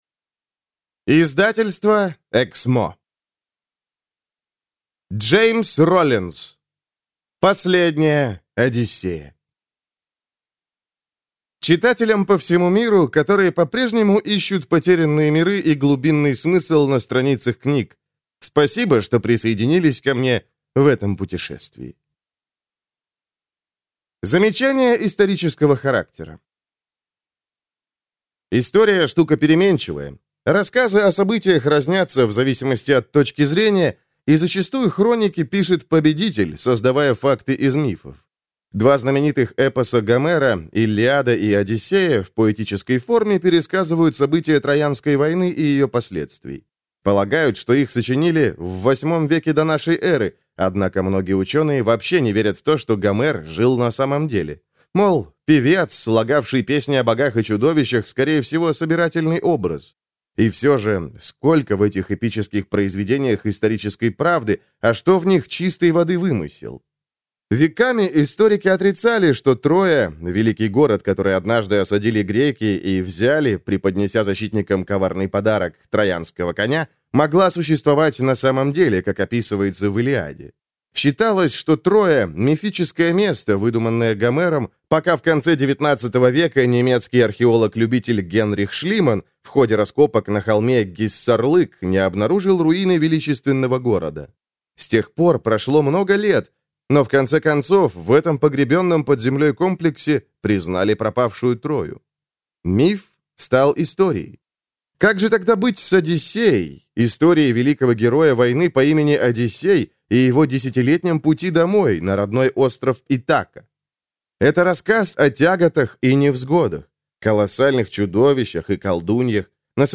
Аудиокнига «Последняя одиссея» в интернет-магазине КнигоПоиск ✅ в аудиоформате ✅ Скачать Последняя одиссея в mp3 или слушать онлайн